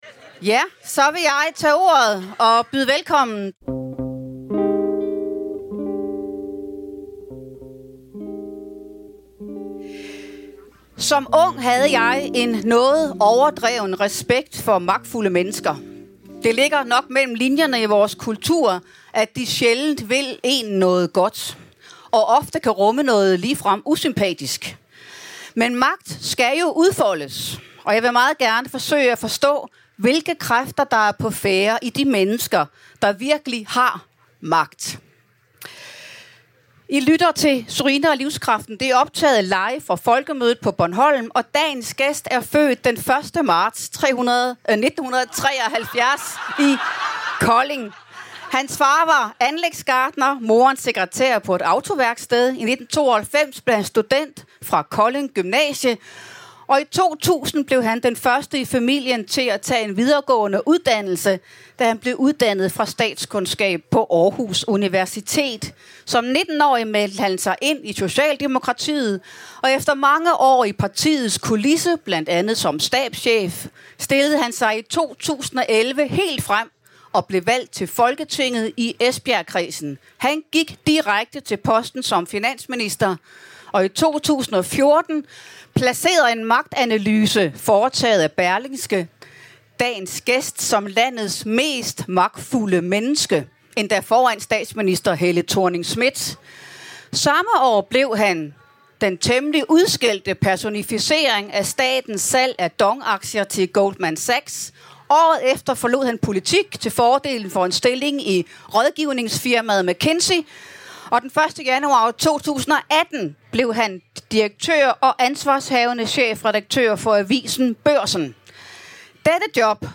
Bjarne Corydon - Live fra Folkemødet